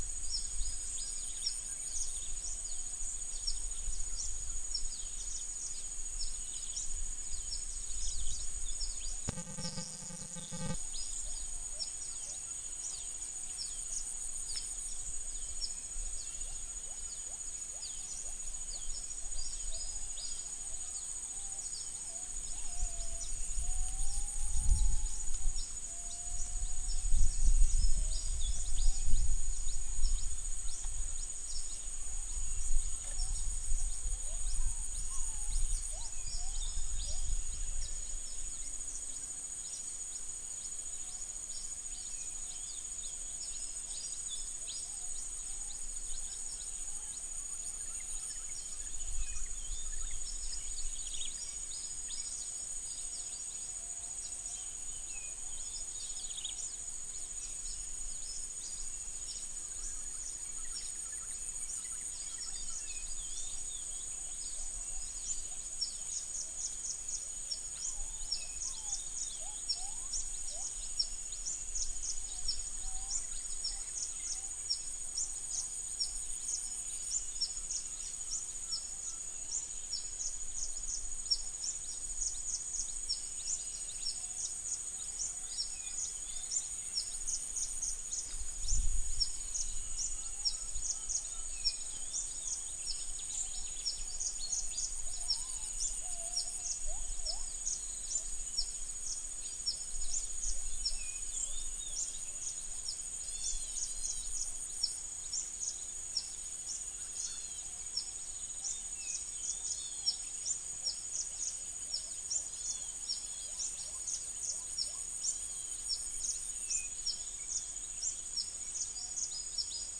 Psilopogon duvaucelii
Eurylaimus harterti
Pycnonotus goiavier
Dicrurus aeneus
Halcyon smyrnensis
Irena puella